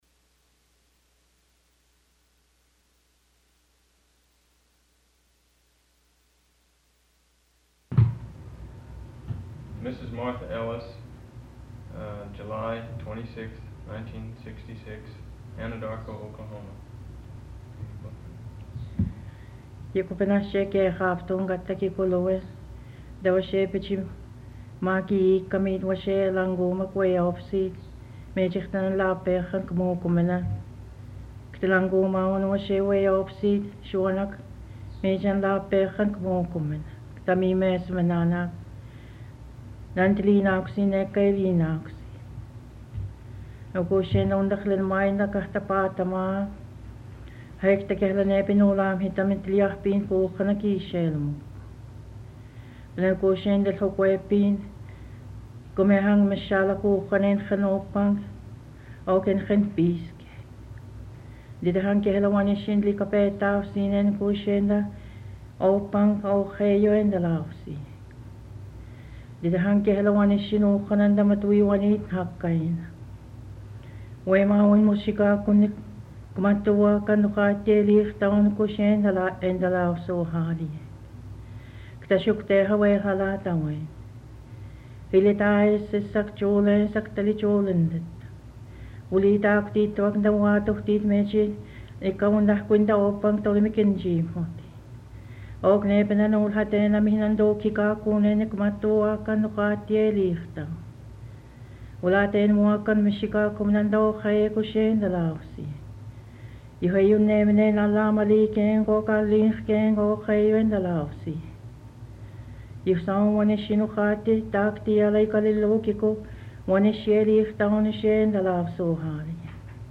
A Prayer and three stories of Rabbit in Unami - sound recordings